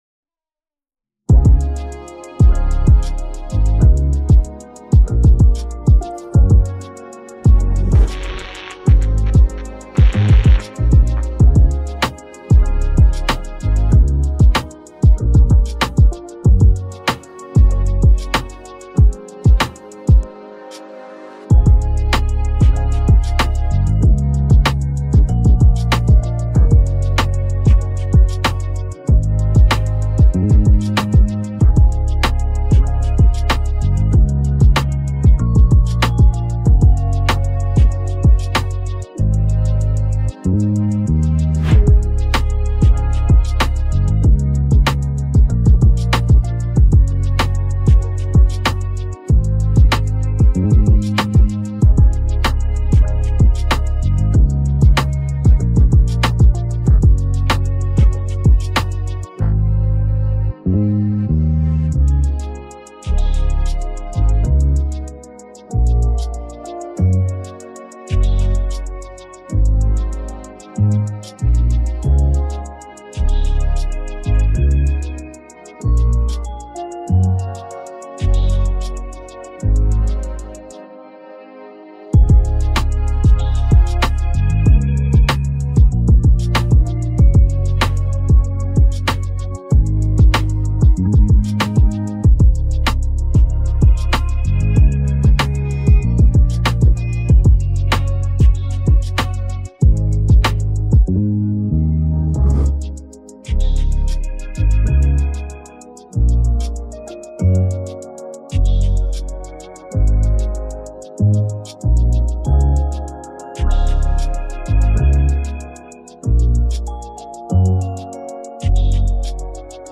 Hip hop hiphop trap beats